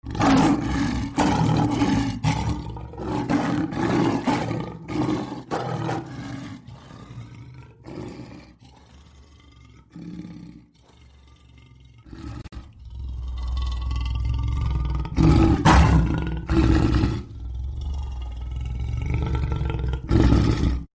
Lion Roars With Growls And Inhales Bouton sonore